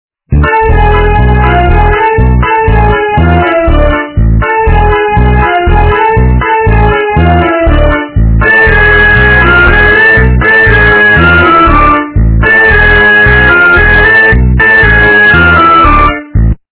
качество понижено и присутствуют гудки
полифоническую мелодию